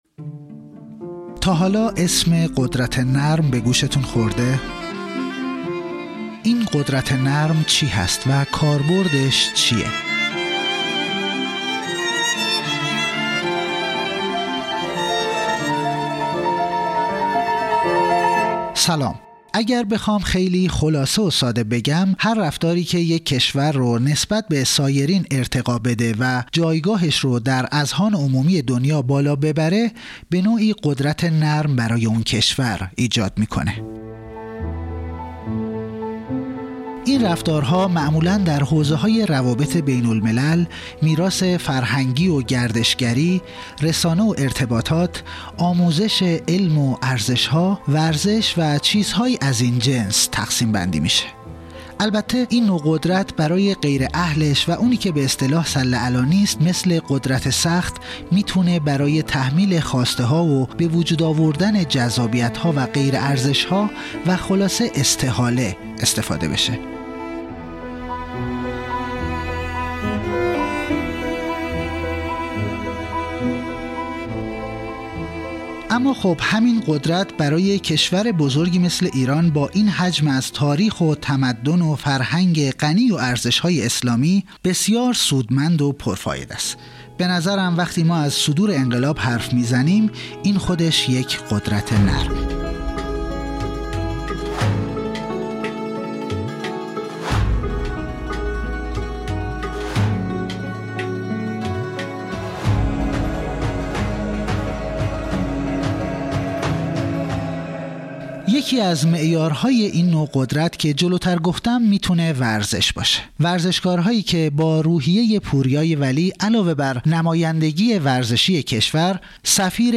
مشروح بیانات در دیدار مدال‌آوران بازی‌های آسیایی و پاراآسیایی هانگژو